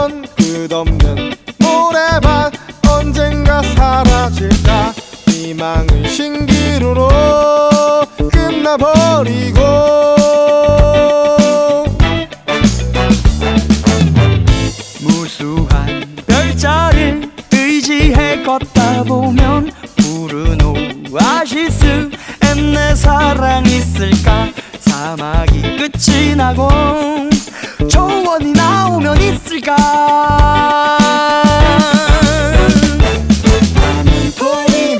Жанр: Танцевальные / Поп / K-pop